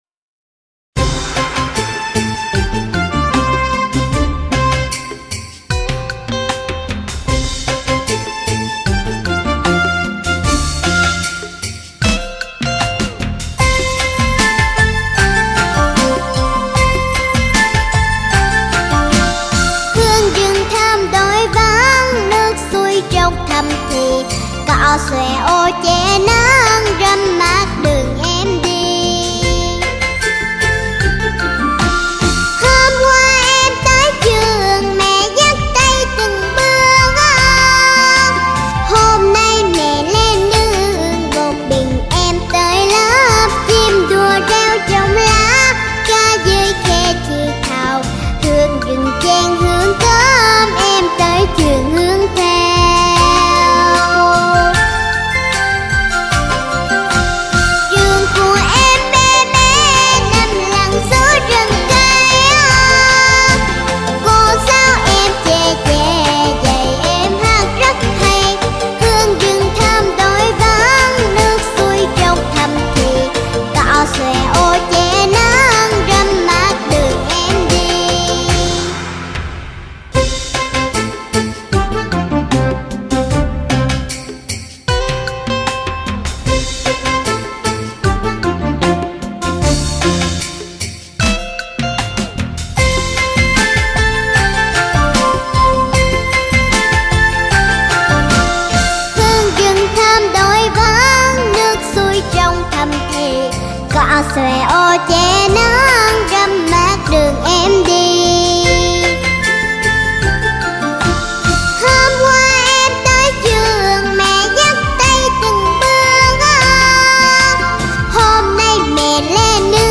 Sách nói | Đi học